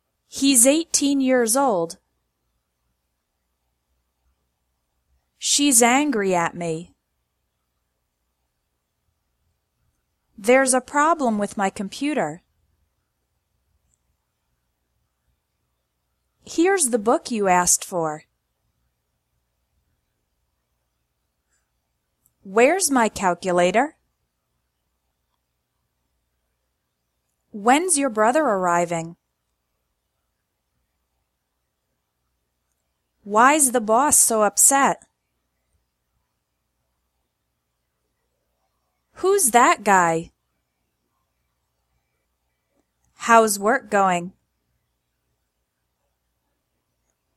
‘s pronounced Z: